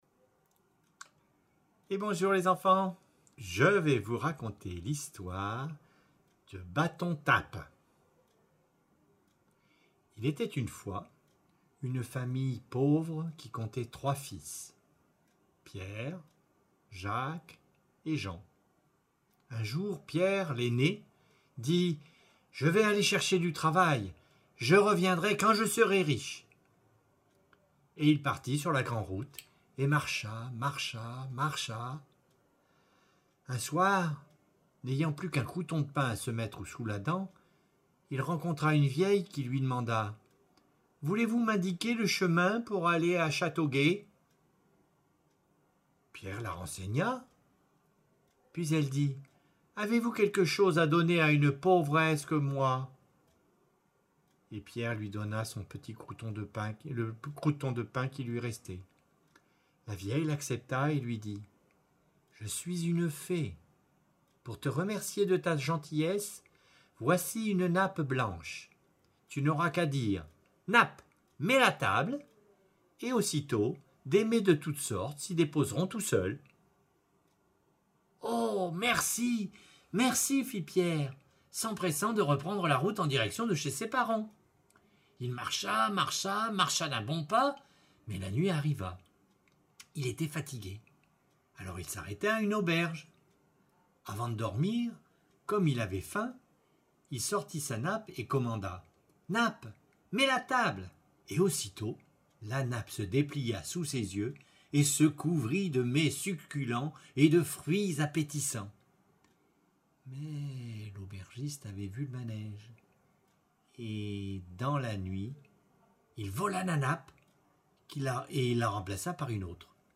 Une histoire racontée